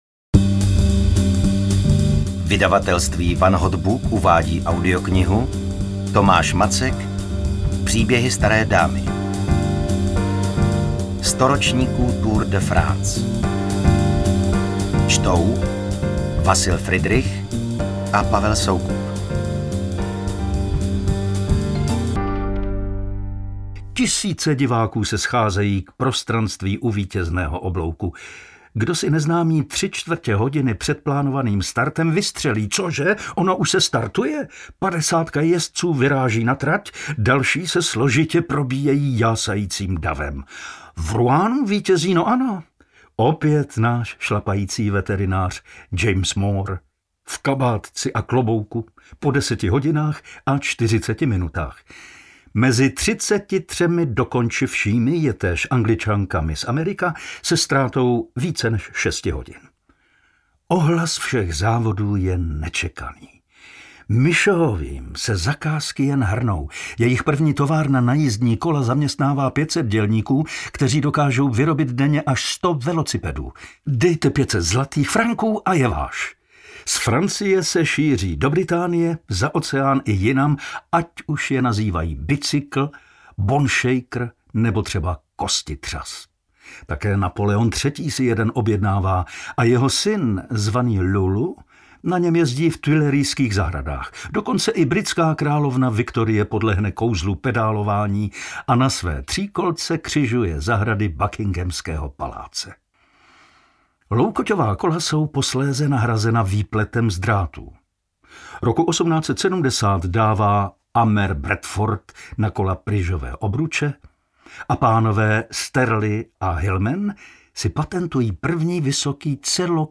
Ukázka z knihy
pribehy-stare-damy-audiokniha